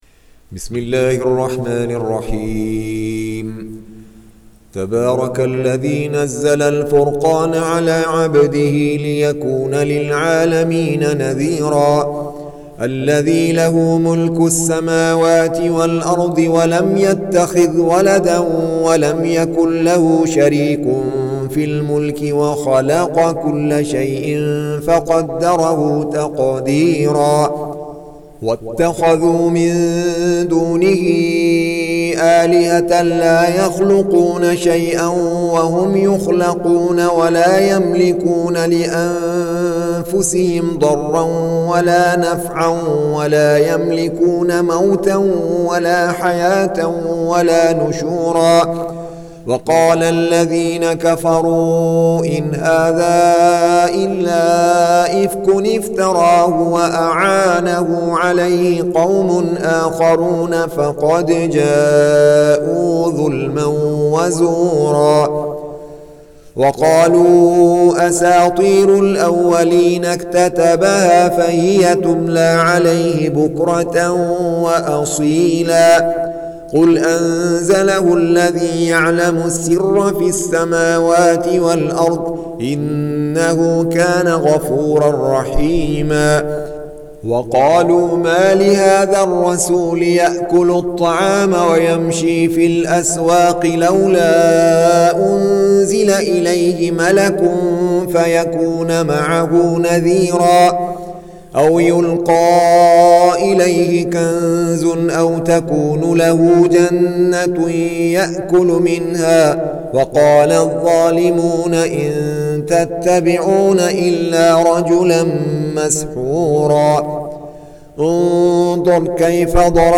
Surah Repeating تكرار السورة Download Surah حمّل السورة Reciting Murattalah Audio for 25. Surah Al-Furq�n سورة الفرقان N.B *Surah Includes Al-Basmalah Reciters Sequents تتابع التلاوات Reciters Repeats تكرار التلاوات